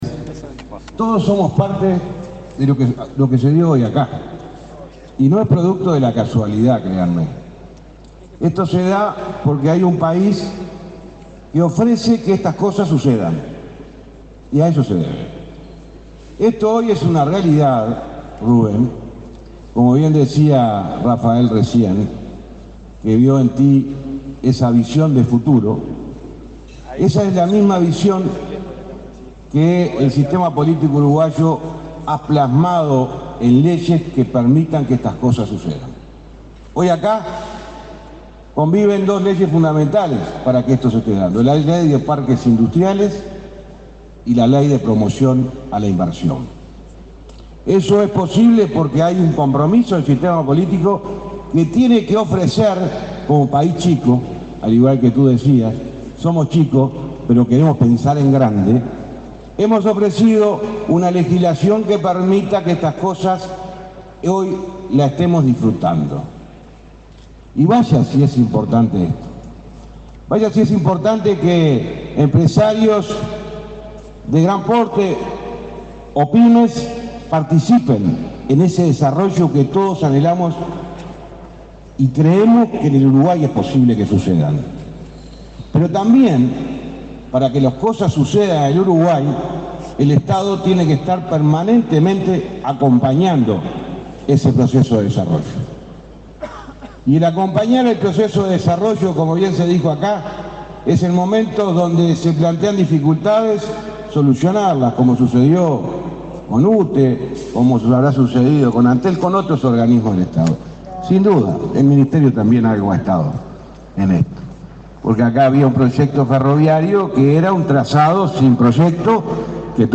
Palabras del ministro de Transporte, José Luis Falero | Presidencia Uruguay
El ministro de Transporte, José Luis Falero, participó, este viernes 8, de la presentación de un nuevo conjunto de emprendimientos del Parque